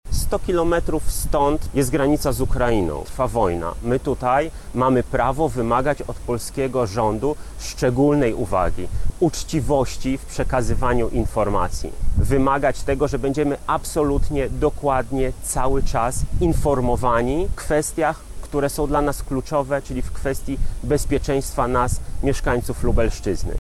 • mówi poseł Michał Krawczyk.